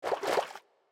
海龟：游动
海龟在游泳时随机播放这些音效
Minecraft_turtle_swim_swim5.mp3